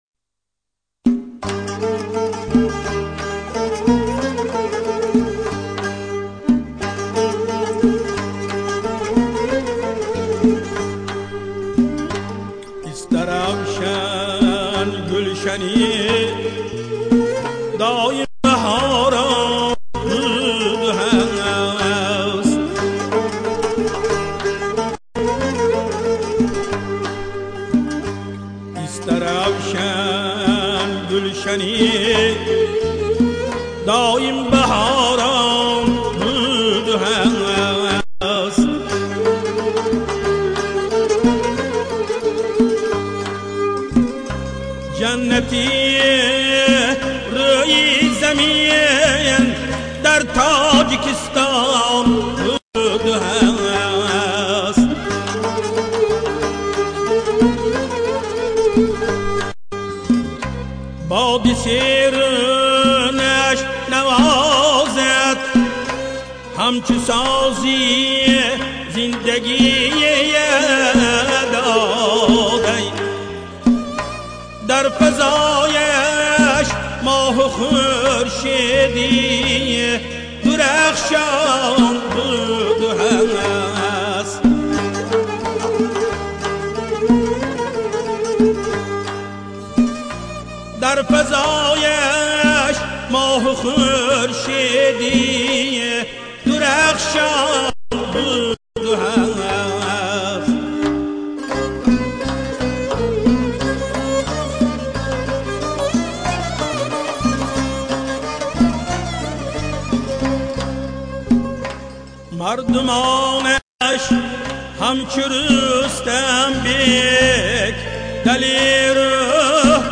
Шашмаком, Халки-Народный